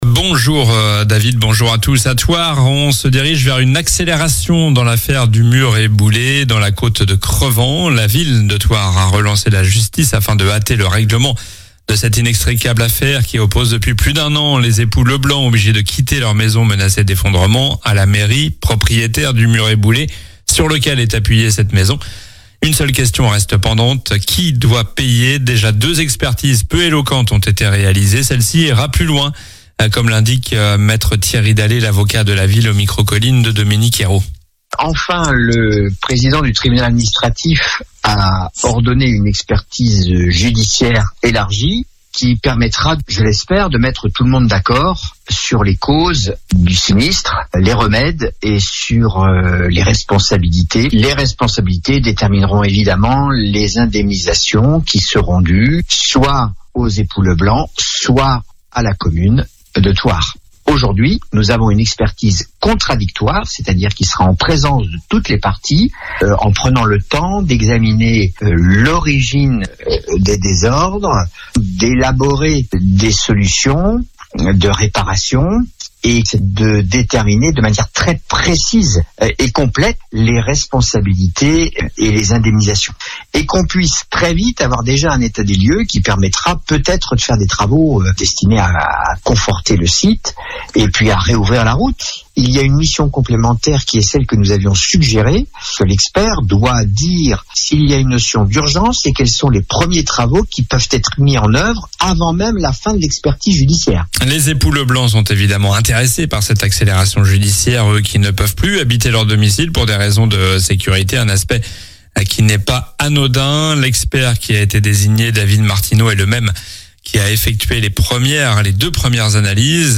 Journal du mardi 22 juillet (matin)